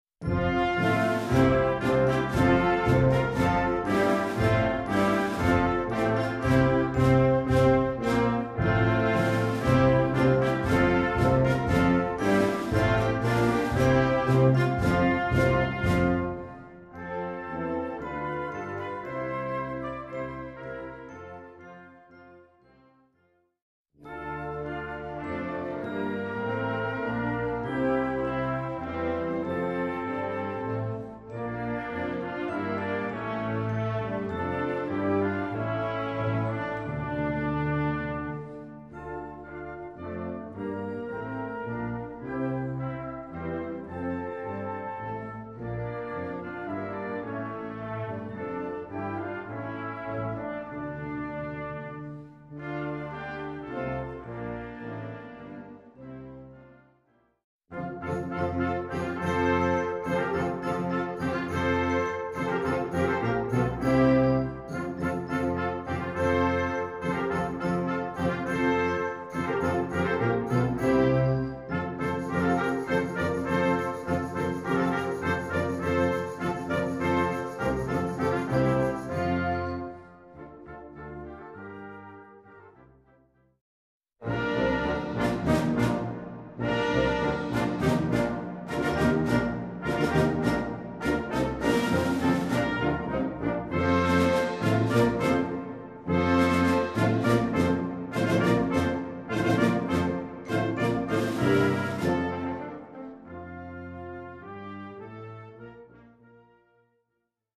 Concert Band ou Harmonie ou Fanfare ou Brass Band